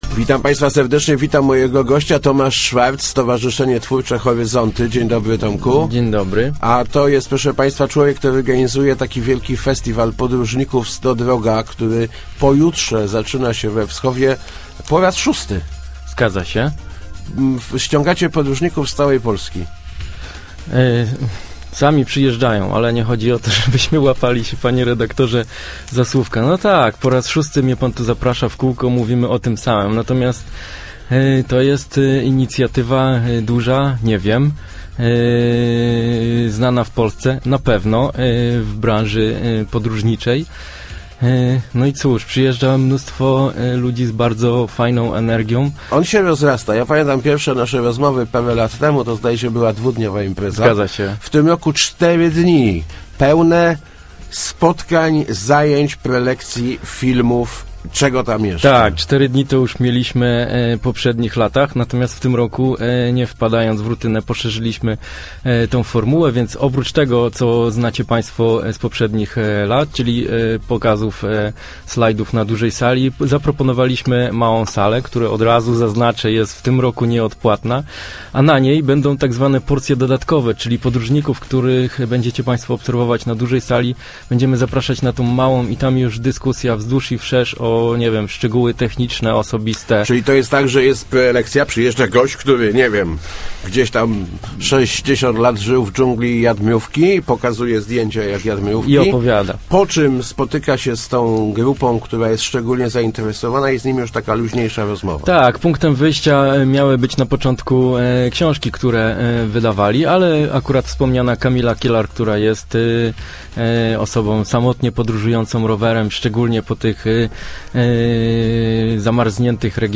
Start arrow Rozmowy Elki arrow Podróżniczy weekend we Wschowie